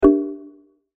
volume_changed.ogg